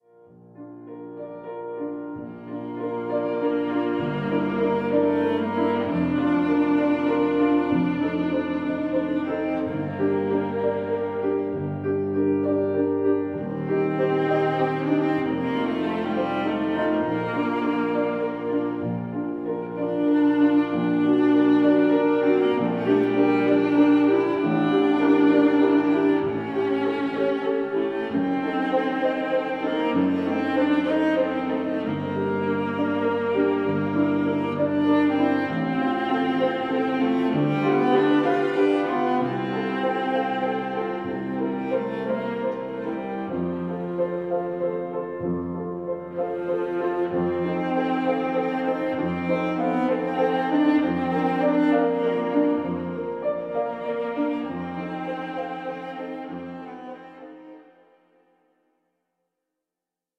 (in der Trauerhalle)
klassische Musik